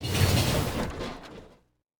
train-tie-6.ogg